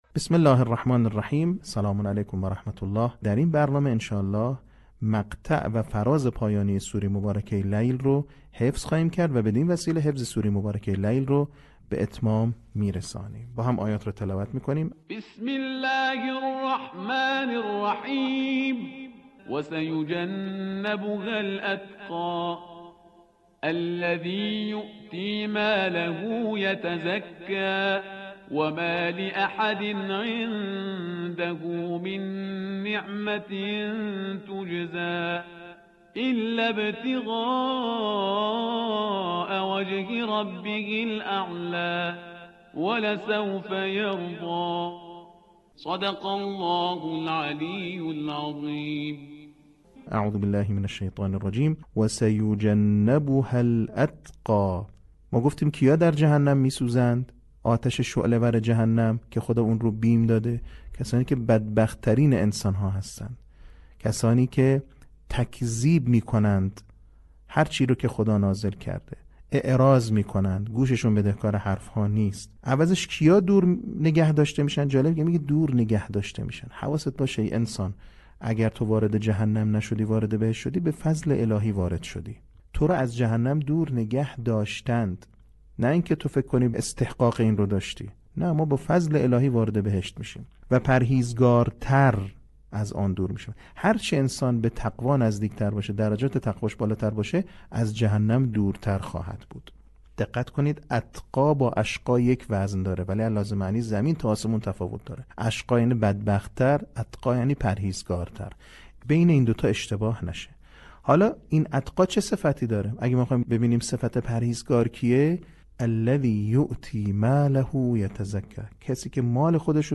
صوت | بخش چهارم آموزش حفظ سوره لیل